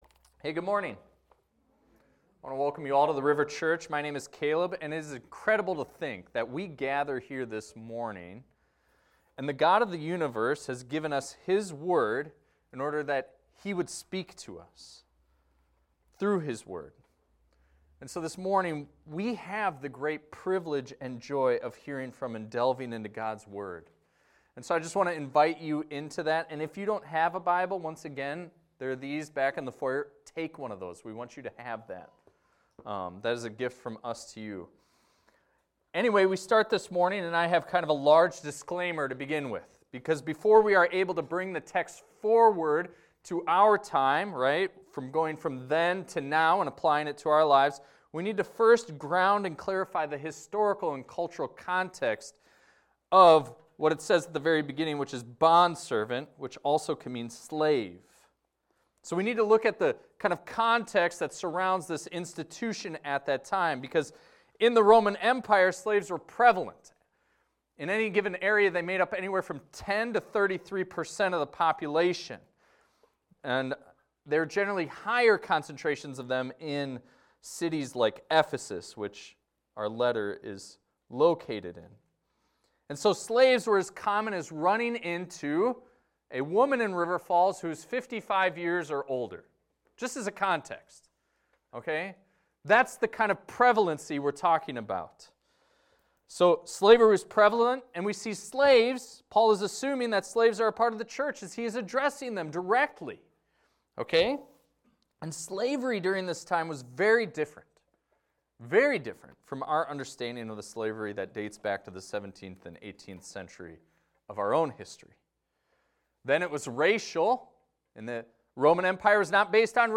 This is a recording of a sermon titled, "Workers and Bosses."